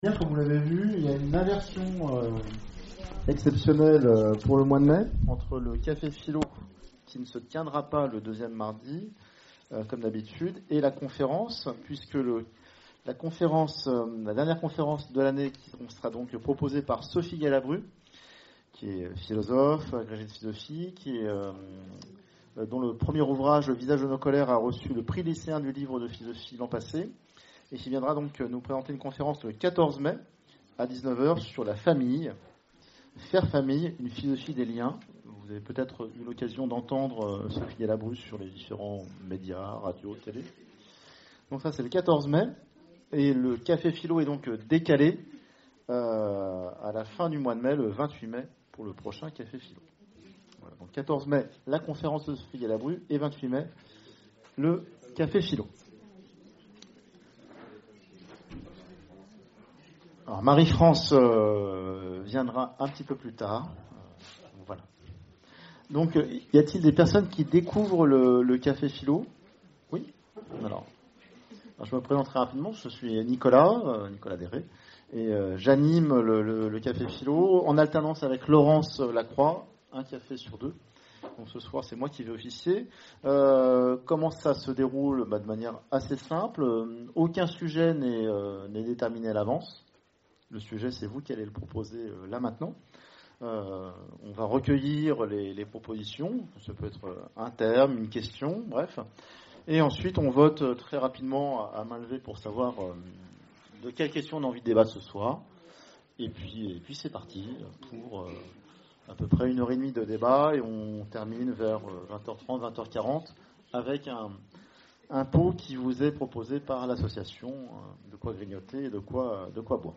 Conférences et cafés-philo, Orléans